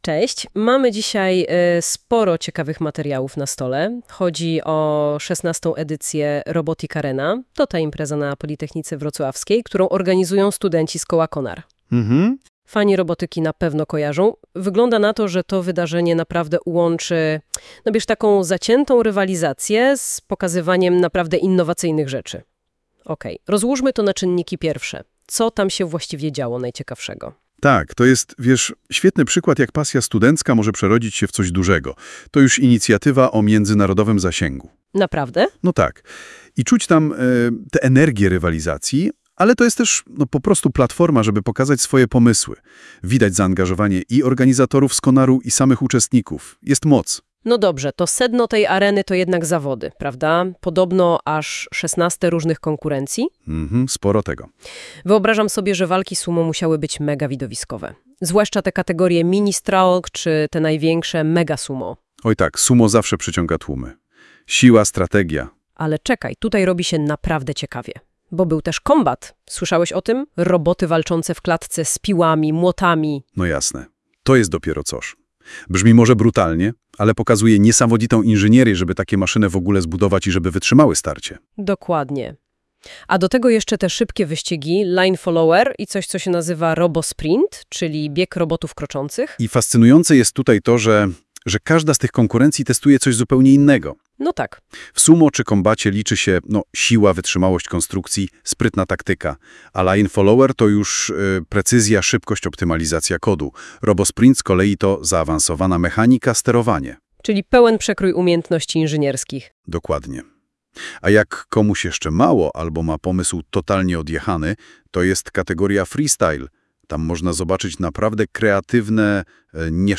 Rozmowa audio ( Notebook LM)